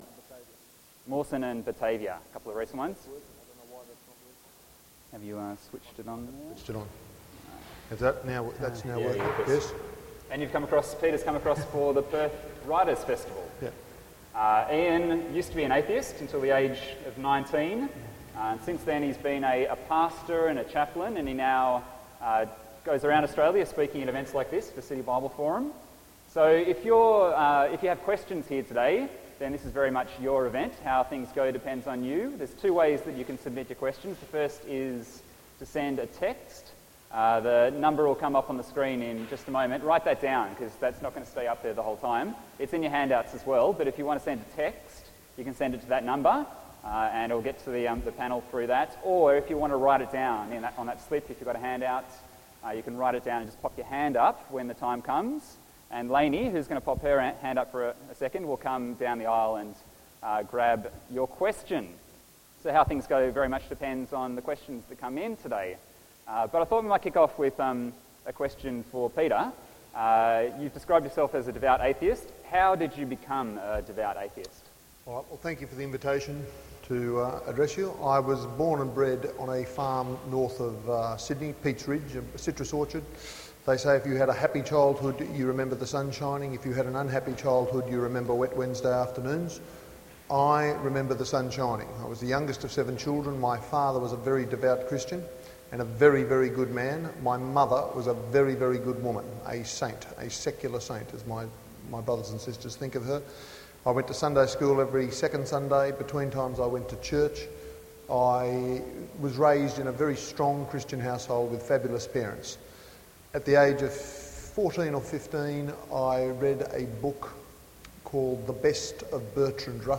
'Is there a God?' Panel